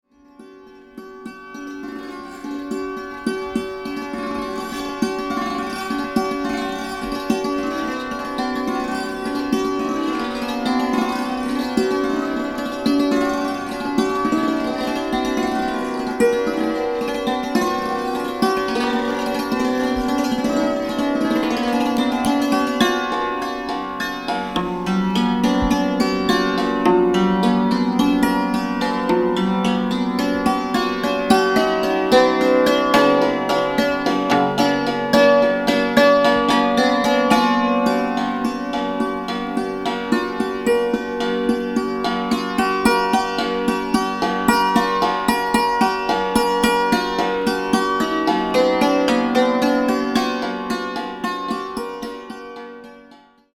台形の箱型共鳴胴に張った鋼鉄製の弦を撥で打って奏でるペルシャ由来の打弦楽器サントゥール。
インド　即興